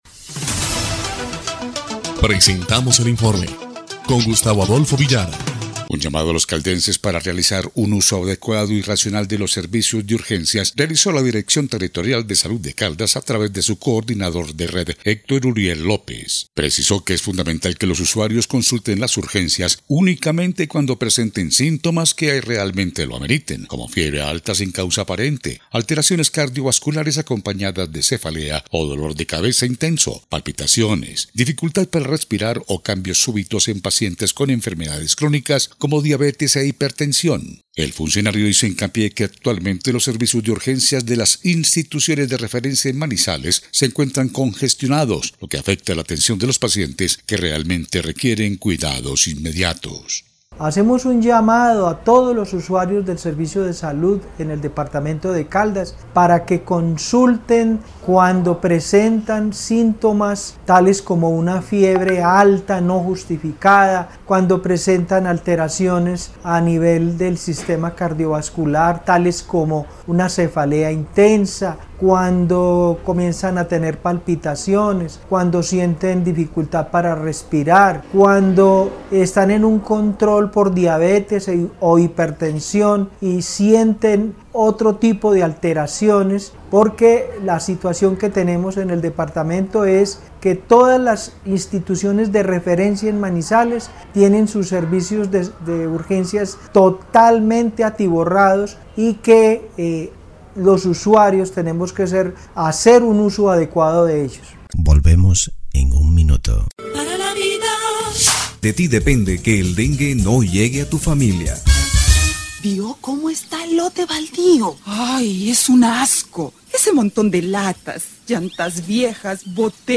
EL INFORME 1° Clip de Noticias del 25 de septiembre de 2025